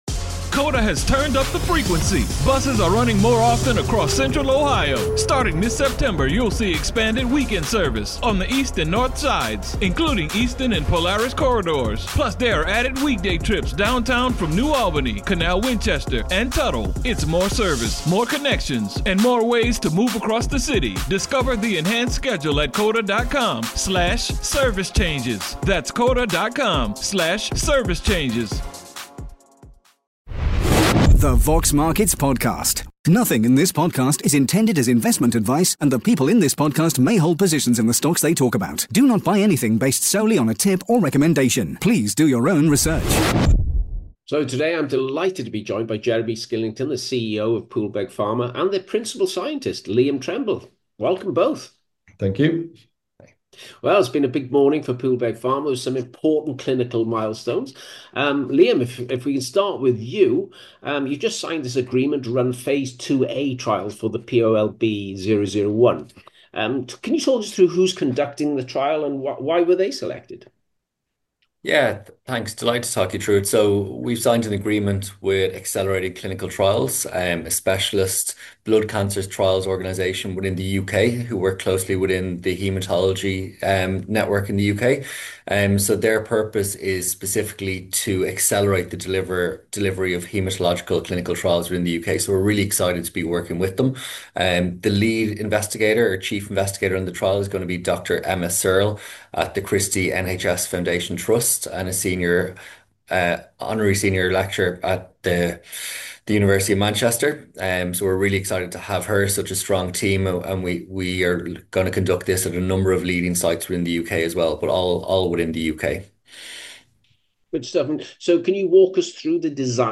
Interview with CEO